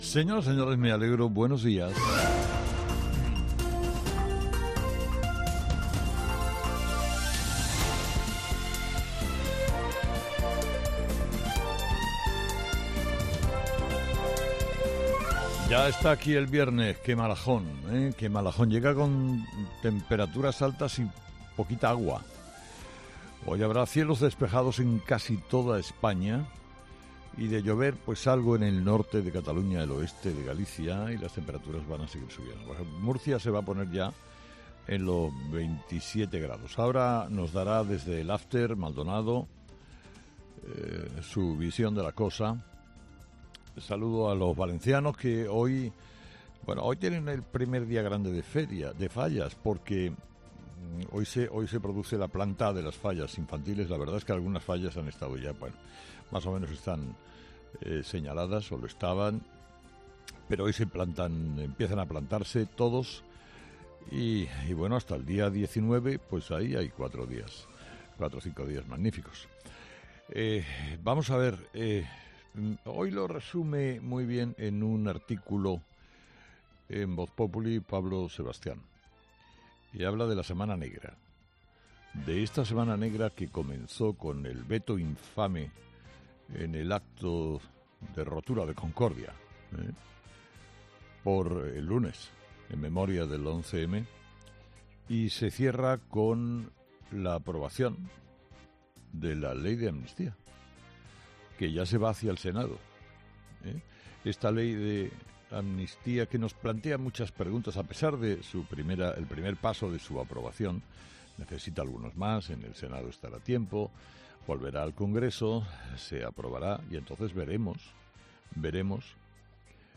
AUDIO: Escucha el análisis de Carlos Herrera a las 06:00 en Herrera en COPE del viernes 15 de marzo